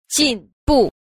2. 進步 – jìnbù – tiến bộ